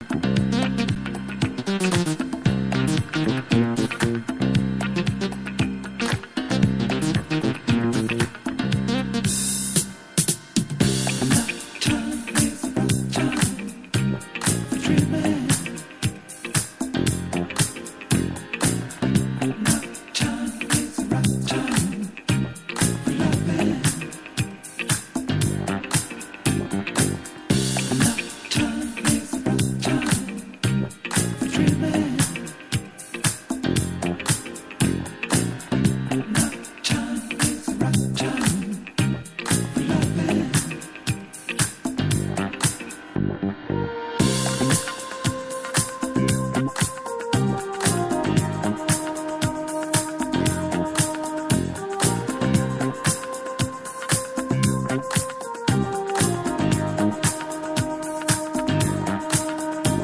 Head straight to the b-side, killer boogie!